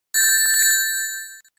sonic-ring-sound2.mp3